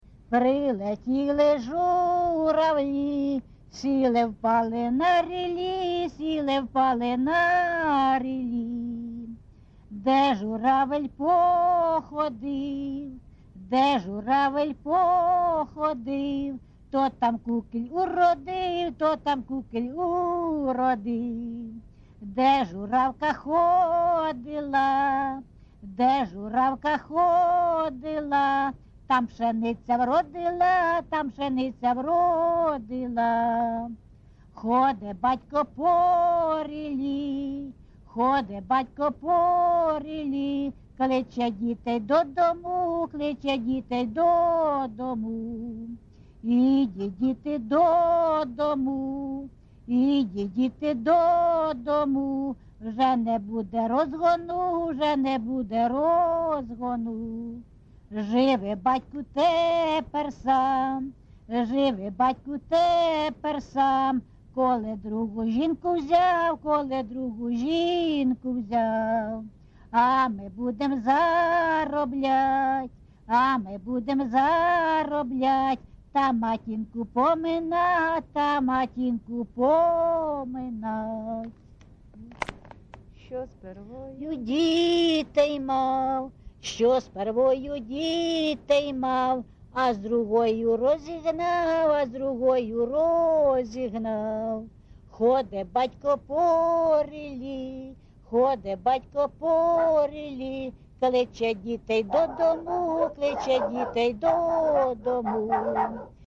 ЖанрПісні з особистого та родинного життя
Місце записус. Андріївка, Великоновосілківський район, Донецька обл., Україна, Слобожанщина